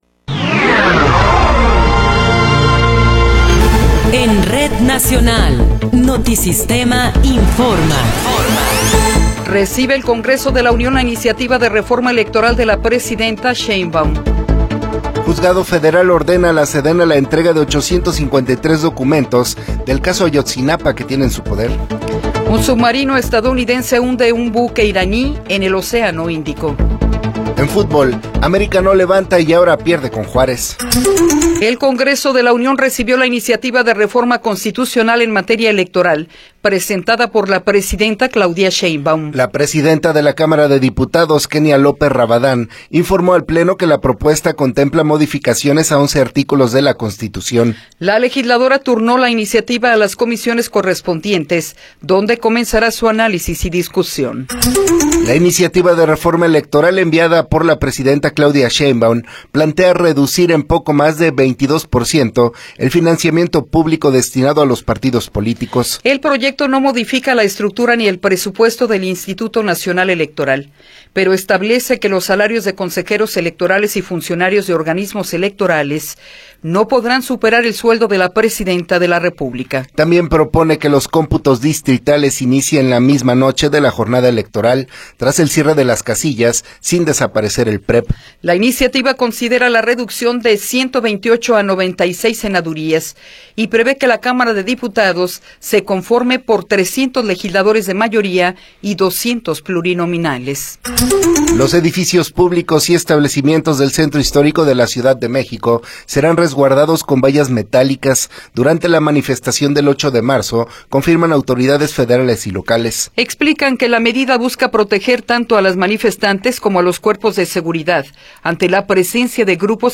Noticiero 8 hrs. – 5 de Marzo de 2026